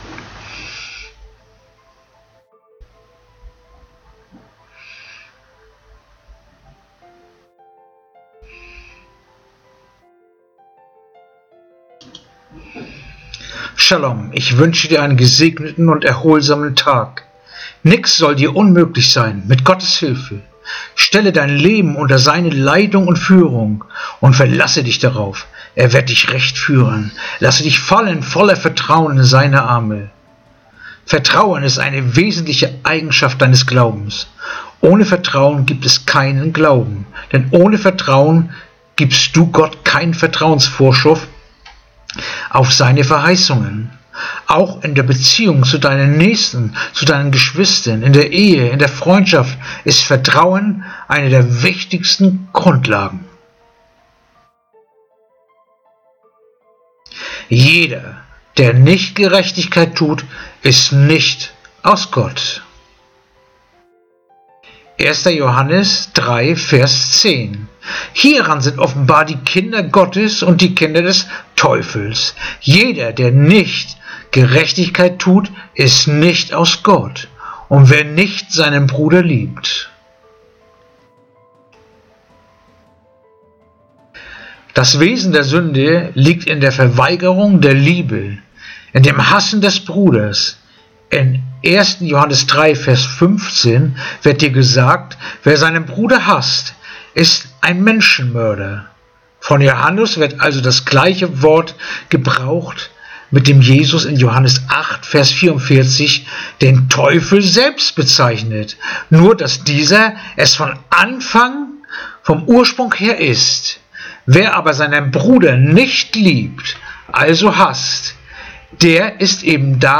Andacht-vom-02-April-1-Johannes-3-10